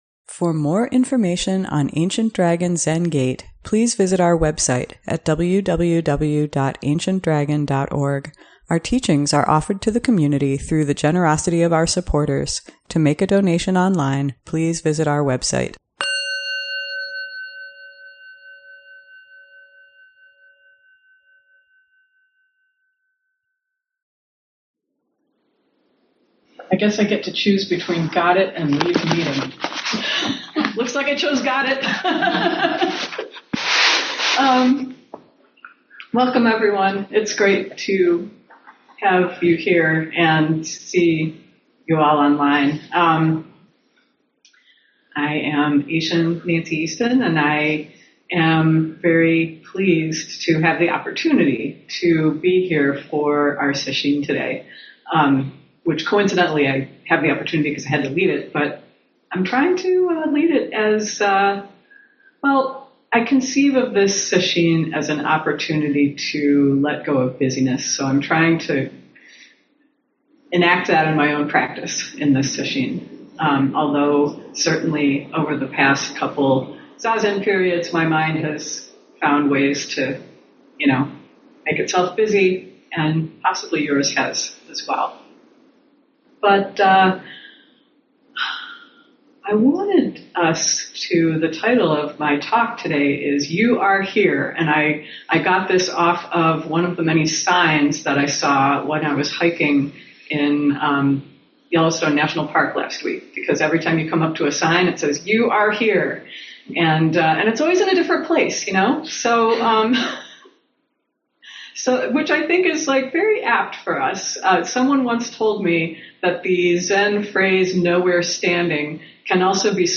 ADZG Sunday Morning Dharma Talk